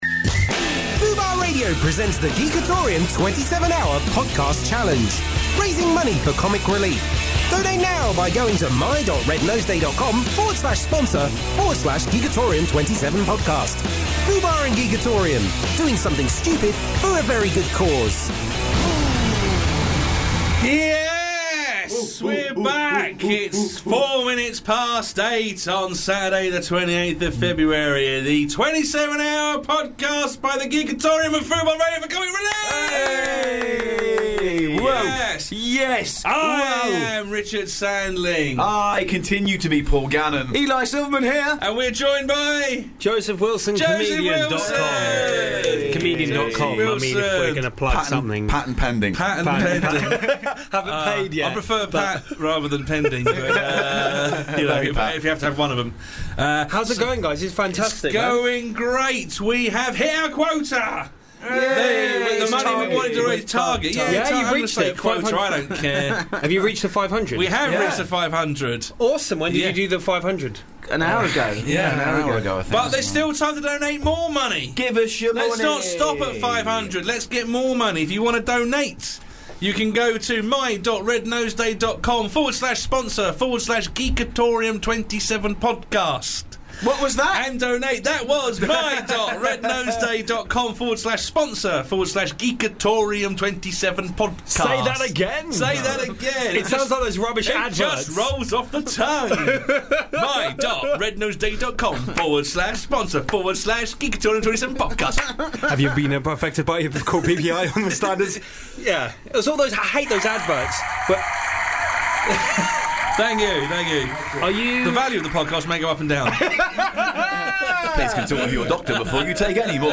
A 27 hour comedy marathon broadcast in aid of Comic Relief! Packed with games, sketches, fund raising challenges and chat.